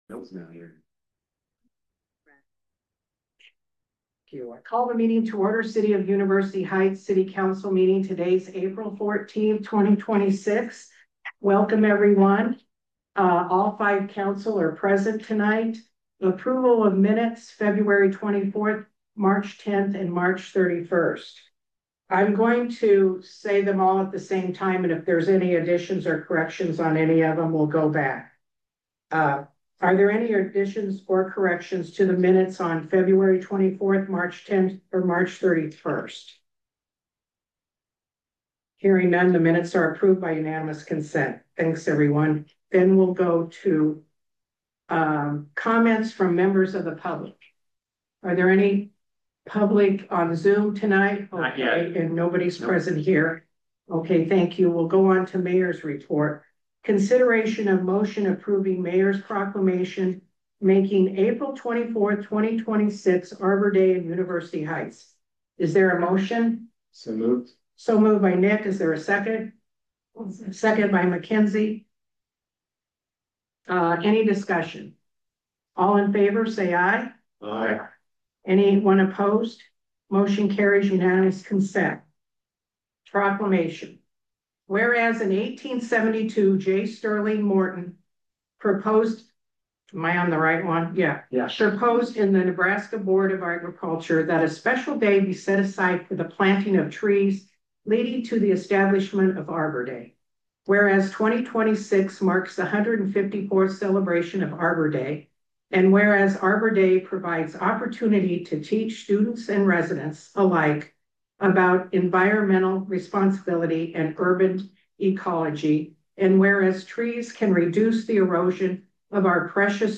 The monthly meeting of the University Heights City Council.